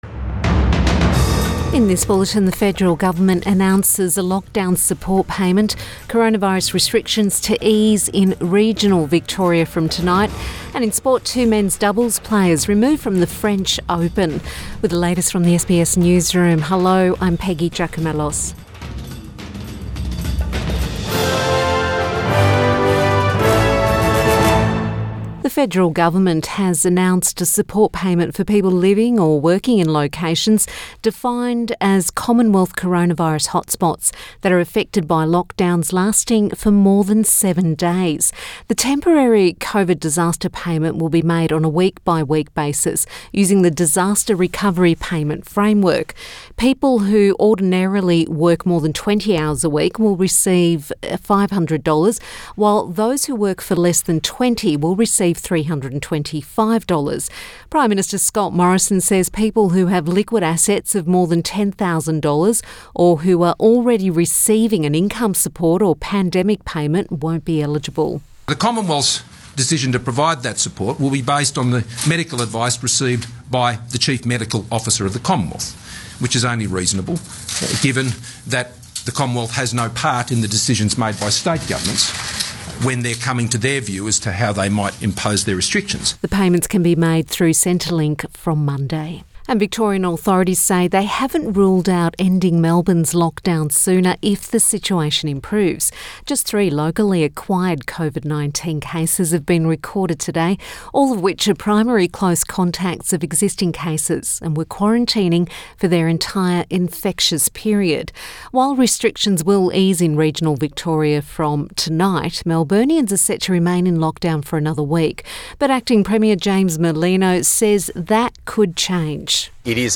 PM bulletin 3 June 2021